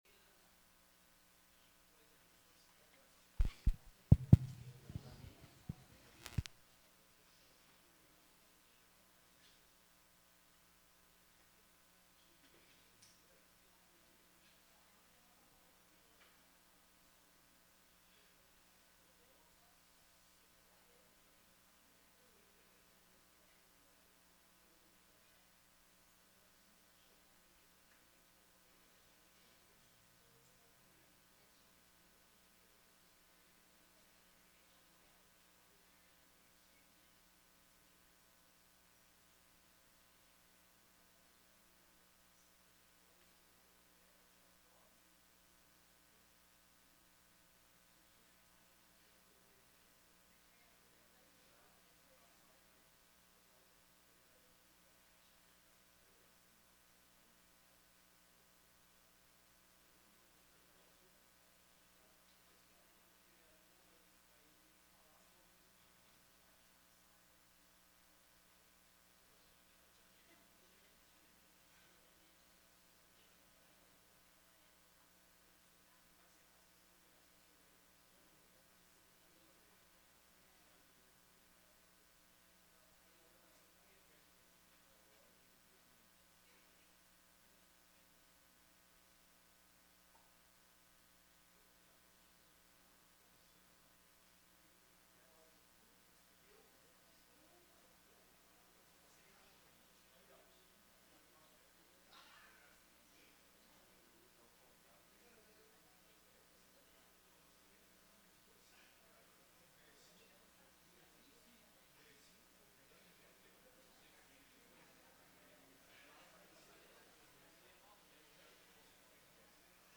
Áudio Sessão 16.12.2024 — Câmara de Vereadores